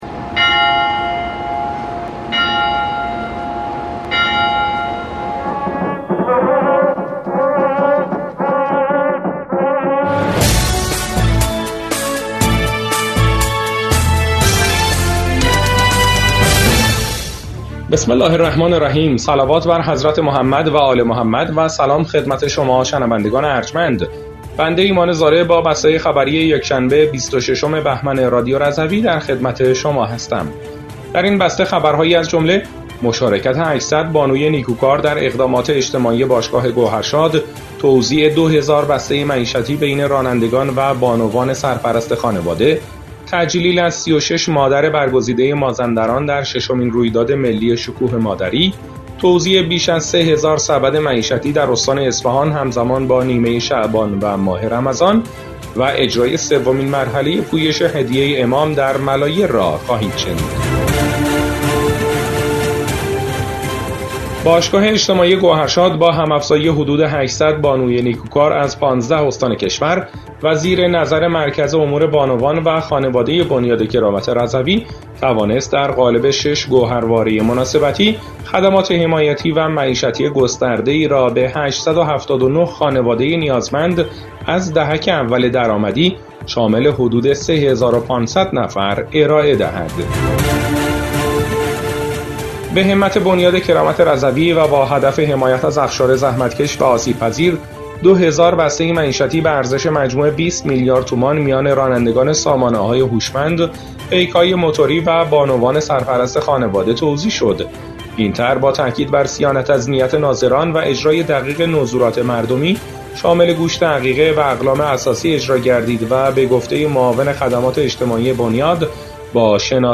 بسته خبری ۲۶ بهمن ۱۴۰۴ رادیو رضوی؛